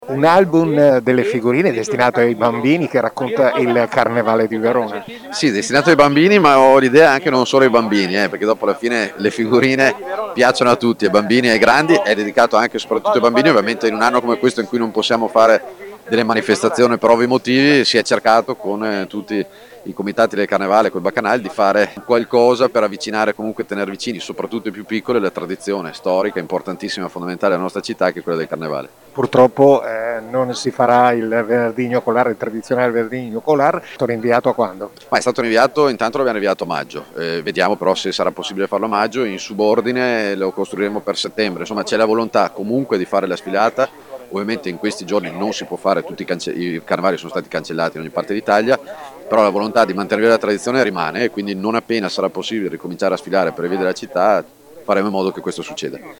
il sindaco Federico Sboarina
Sindaco-di-Verona-Federico-Sboarina-sul-carnevale.mp3